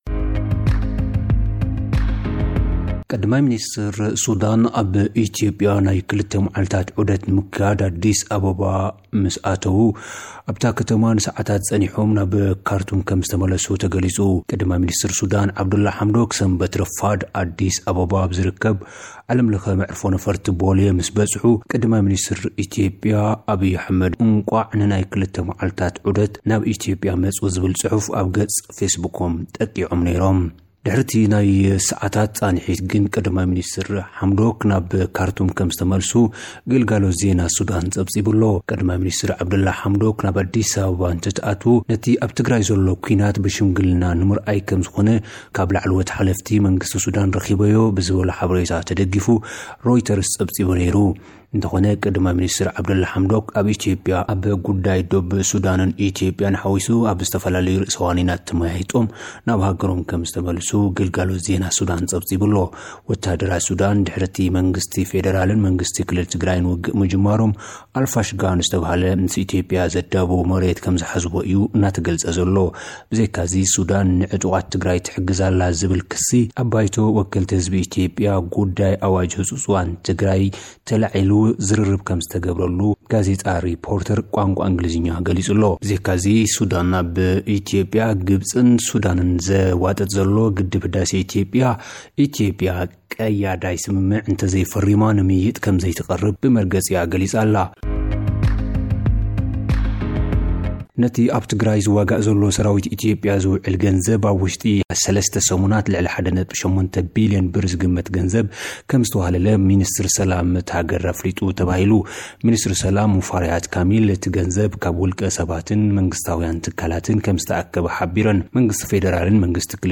ግብጺ ኣብ መንጎ ሓይሊታት ትግራይን ፈደራል መንግስቲን ክዓርቕ ትብል፡ መንግስቲ ፈደራል ንግብጺ ኣብ ዘይጉዳይኪ ኣይትፈትፍቲ ይብላ። (ጸብጻብ)